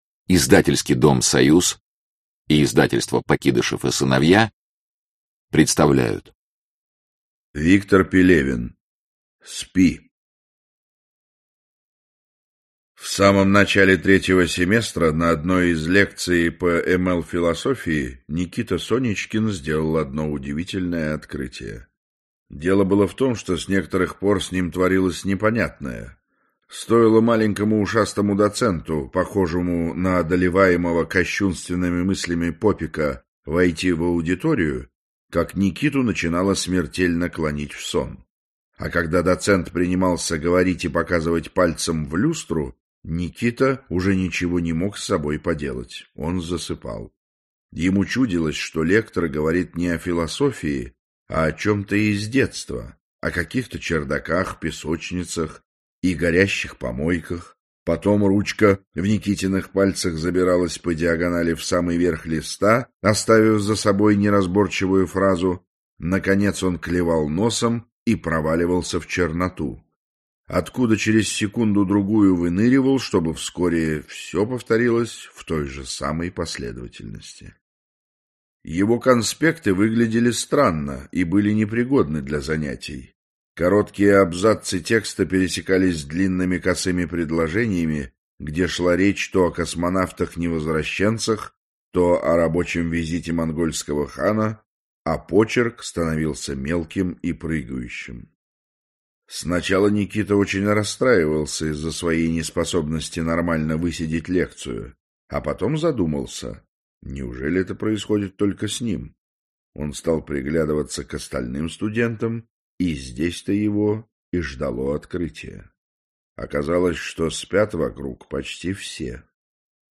Aудиокнига Спи Автор Виктор Пелевин Читает аудиокнигу Максим Суханов.